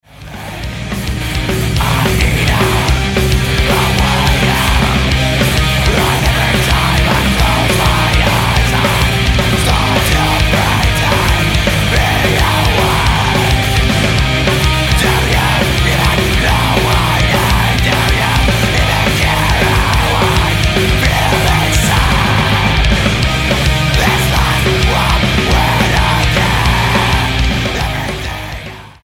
STYLE: Hard Music